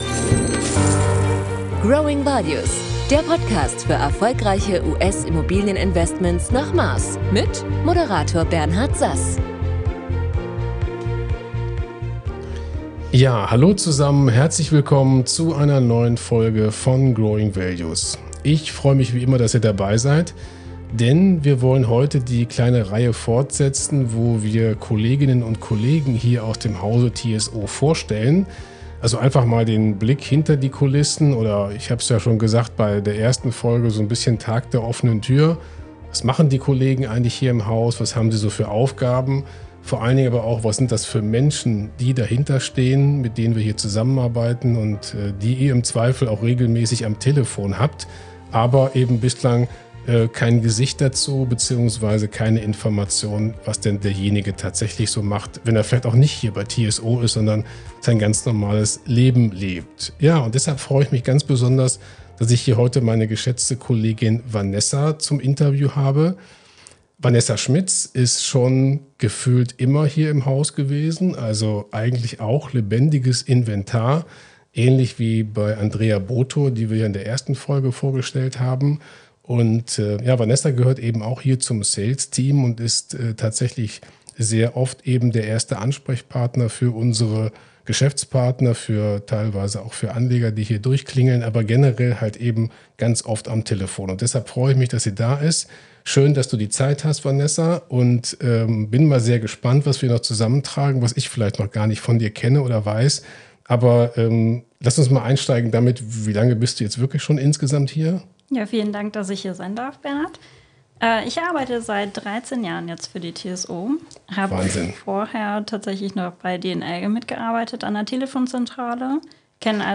Freut euch auf ein interessantes Gespräch und nutzt die Gelegenheit, das Team von TSO näher kennenzulernen.